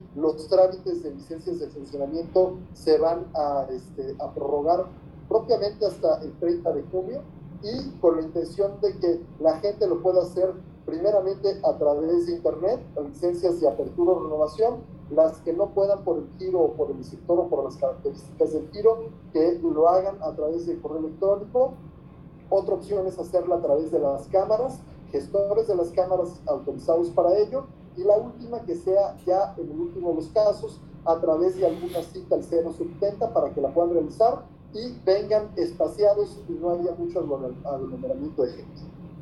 Audio de Genaro Montes Díaz, Secretario de Desarrollo Sostenible.